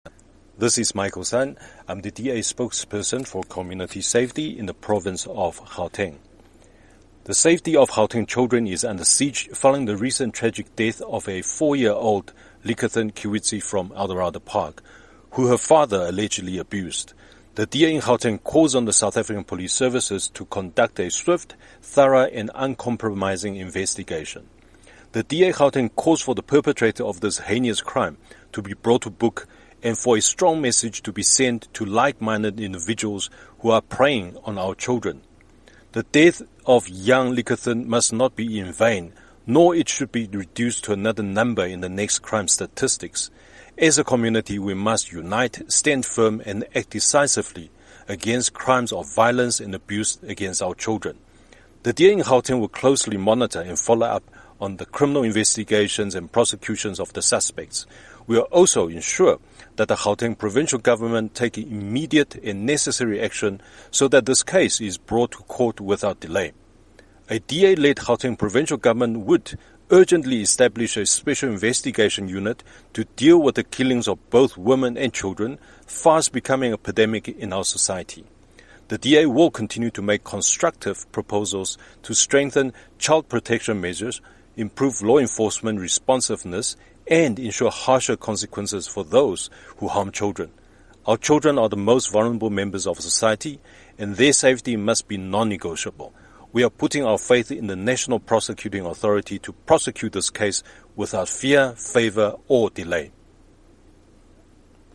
soundbite by Michael Sun MPL.